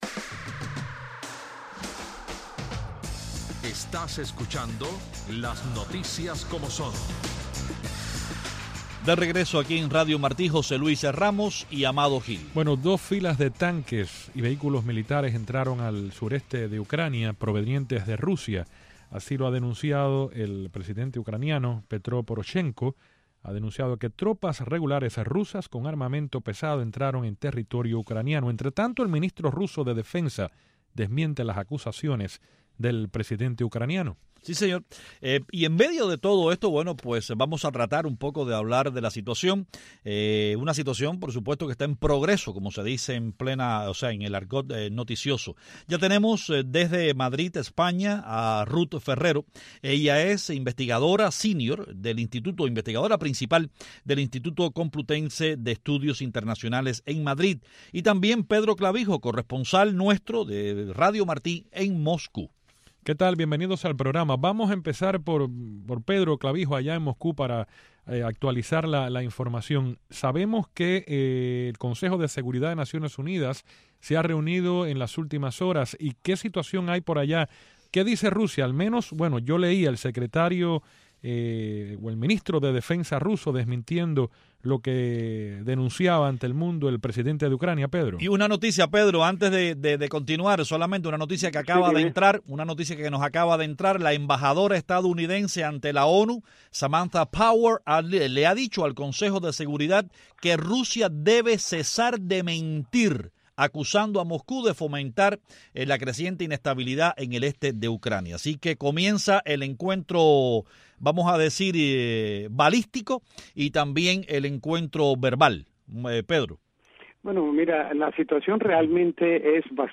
Y desde el vaticano el monseñor Dionisio García nos comenta el orgullo y la satisfaccion que ha experimentado al presenciar la ceremonia en la que se ha colocado una imagen de nuestra virgencita de la Caridad del Cobre , en los jardines del Vaticano.